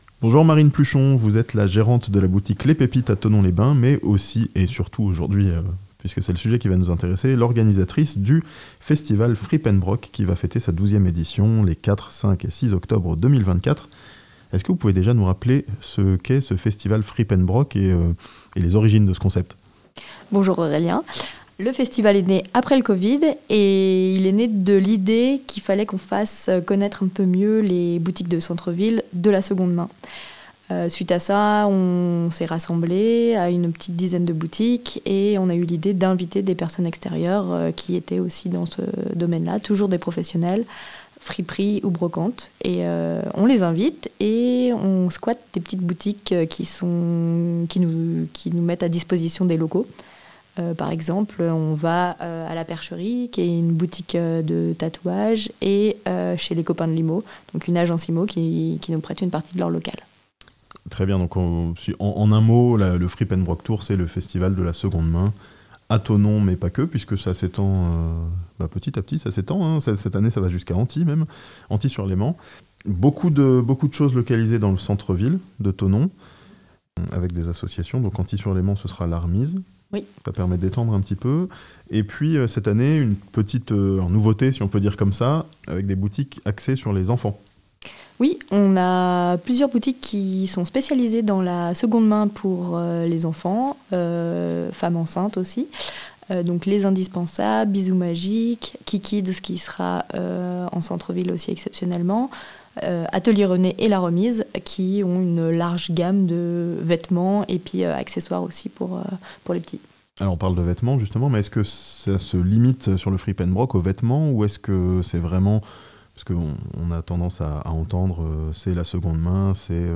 12ème édition du festival de la seconde main, Frip'n'broc, à Thonon ce weekend (interview)